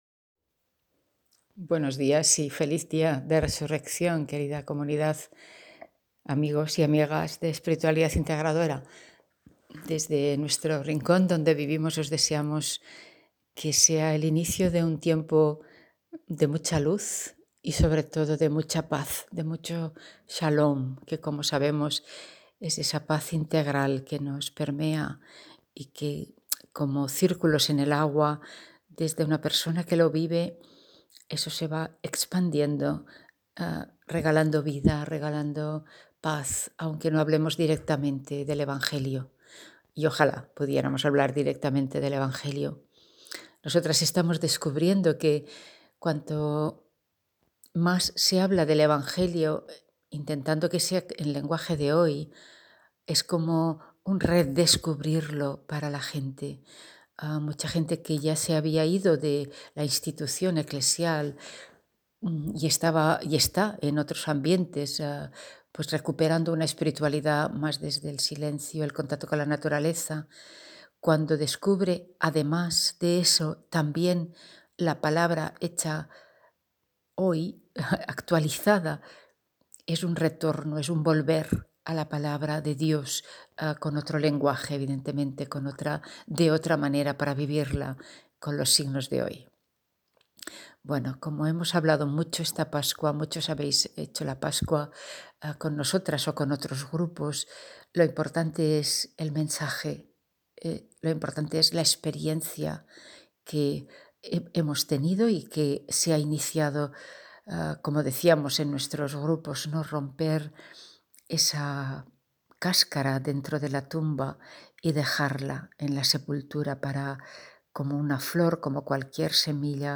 OLYMPUS DIGITAL CAMERA Desliza la pantalla para ver más imágenes Reflexión espiritualidadintegradoracristiana